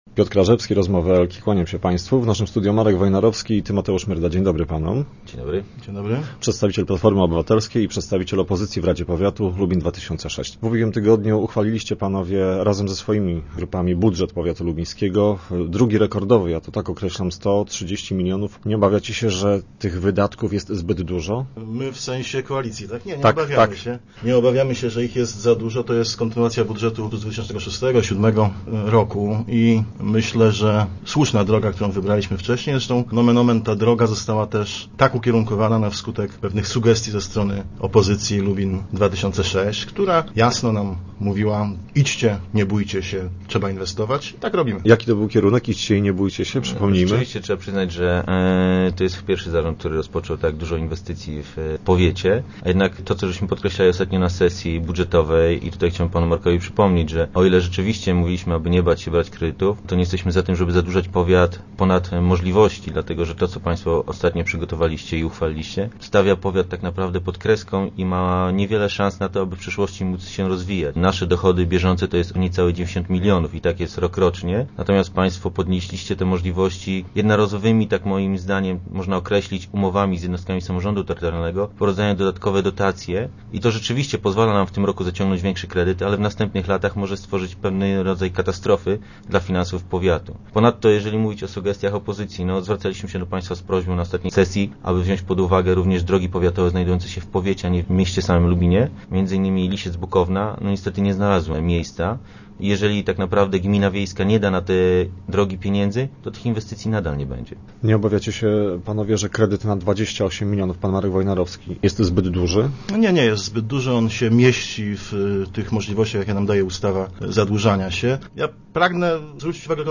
Tymoteusz Myrda i Marek Wojnarowski byli gośćmi Rozmów Elki.